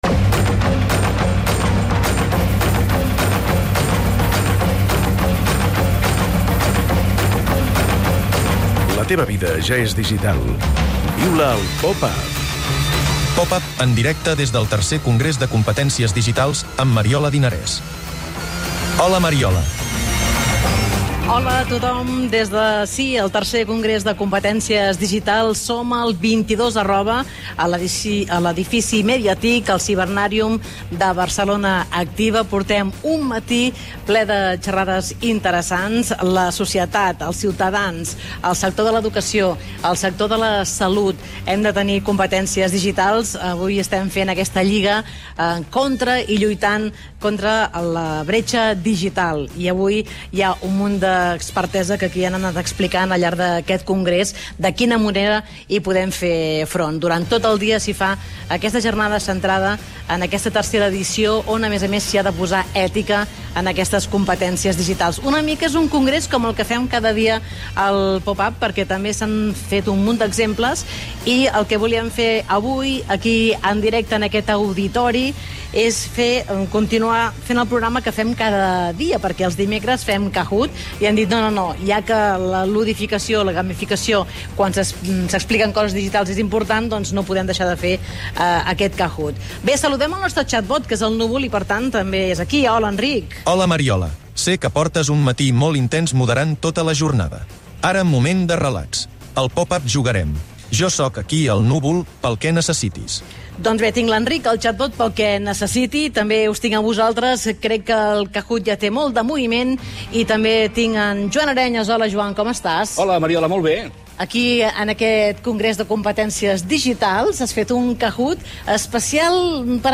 Beschreibung vor 3 Jahren Programa especial des del Cibernàrium on se celebra el Congrés de Competències Digitals.